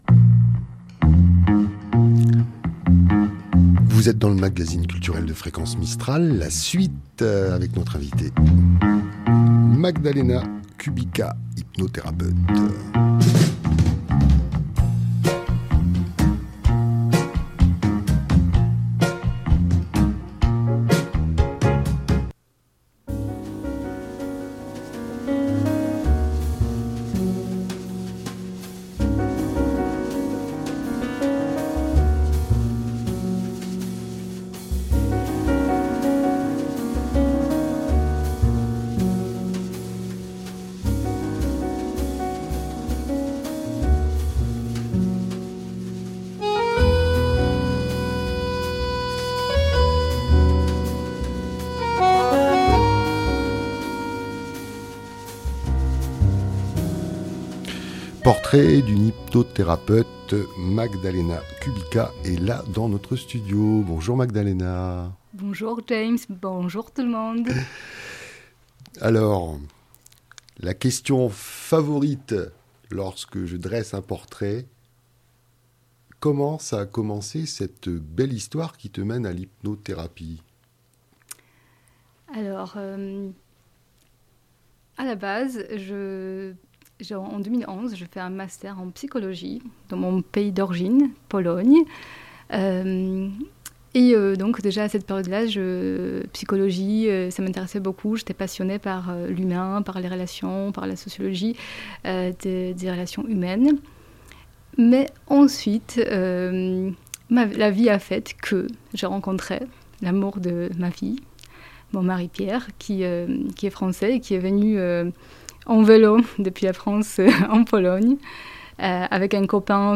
Un moment de partage calme et tranquille pour naviguer dans la joie avec les tempêtes de l'existence. Une invitation à la prise de conscience du sens donné à notre propre vie sur l'étendue des champs des possibles...